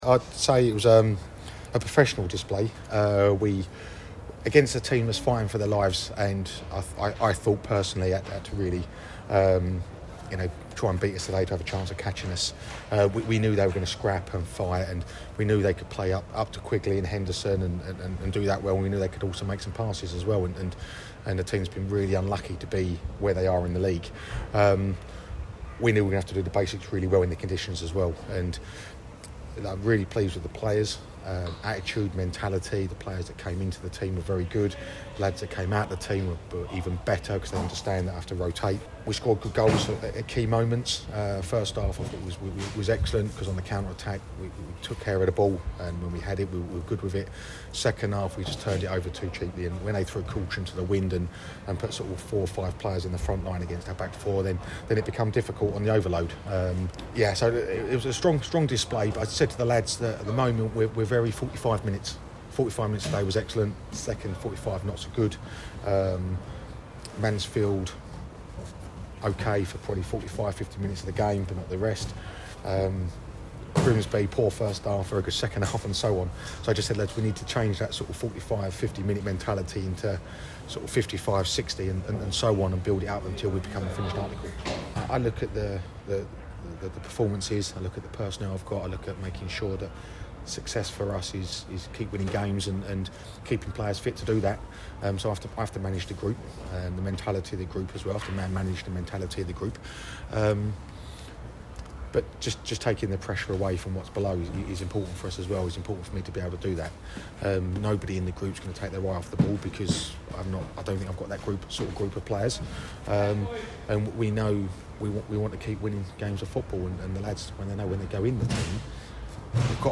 Gillingham's hopes of league two survival have been given another boost as they beat Rochdale. It was a convincing 2-0 victory away from home against the side currently at the bottom of the table. Gills manager Neil Harris spoke to us after the match.